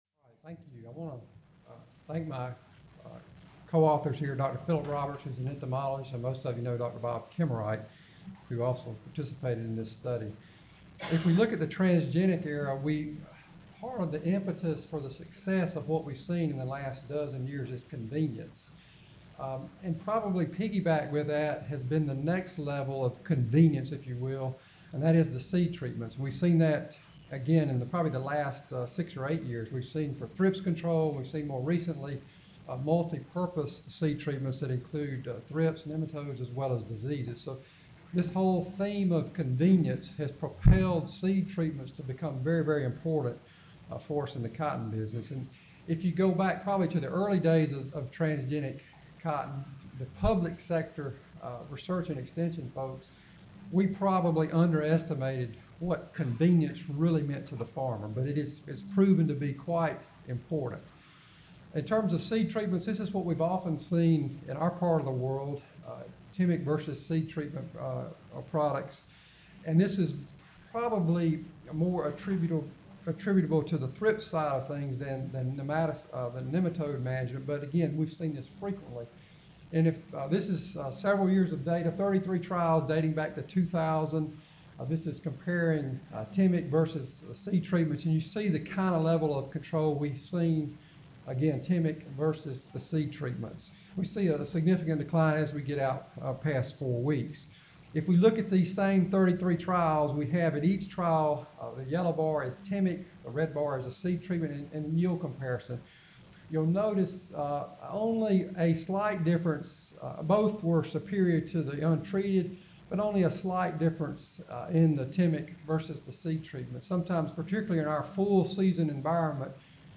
Potential Implications of Thrips Control for Nematode Management Recorded presentation